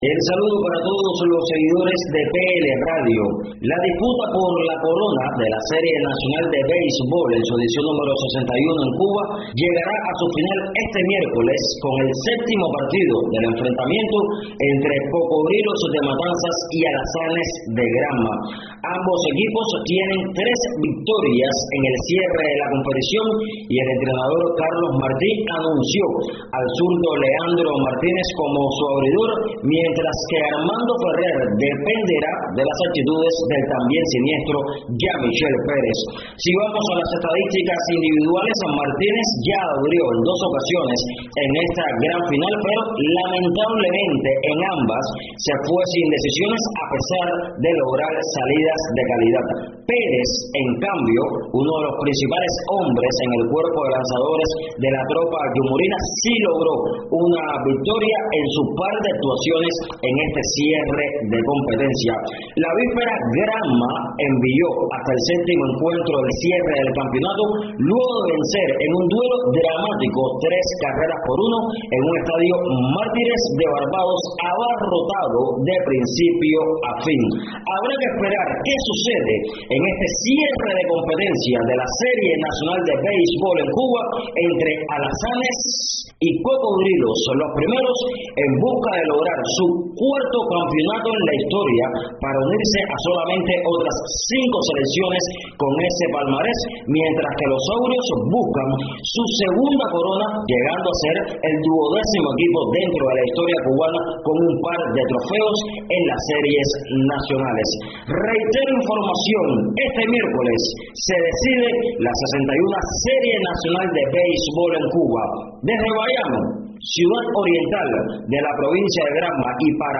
desde Bayamo, Cuba.